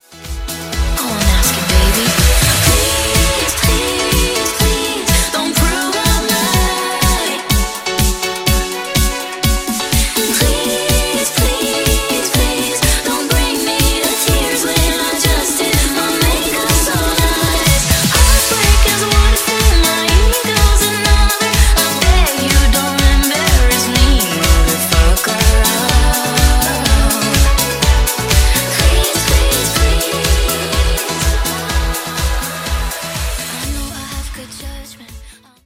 Dj Intro Outro
Genres: 2000's , RE-DRUM , TOP40
Clean BPM: 98 Time